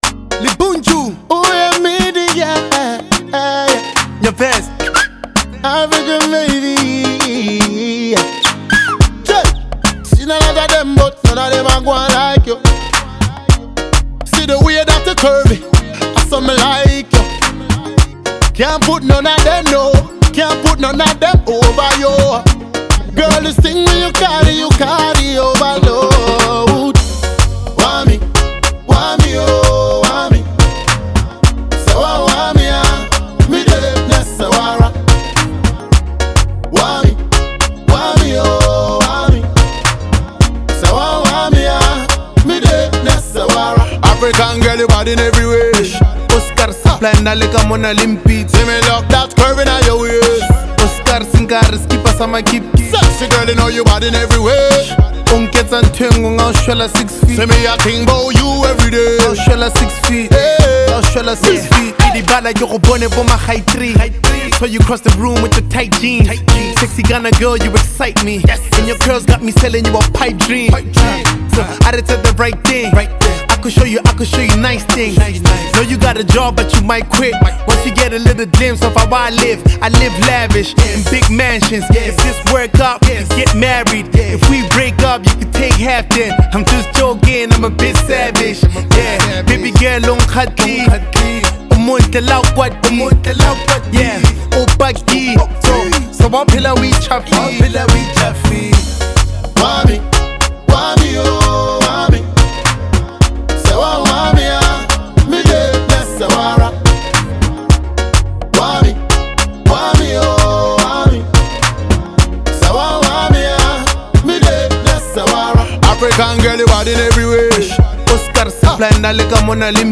dancehall and hip-hop fusion